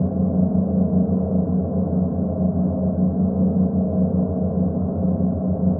hum.wav